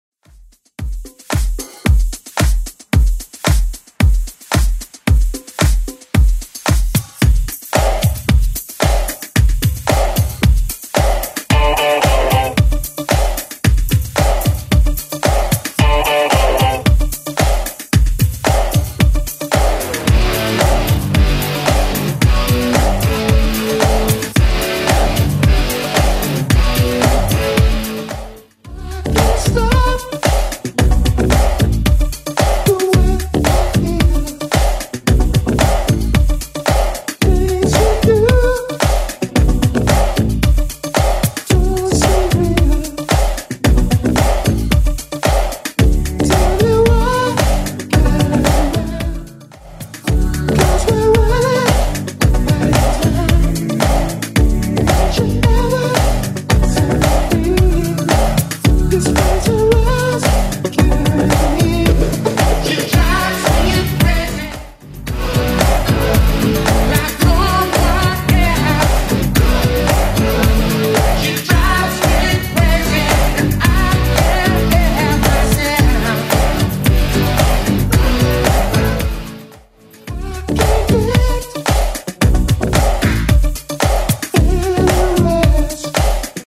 BPM: 112 Time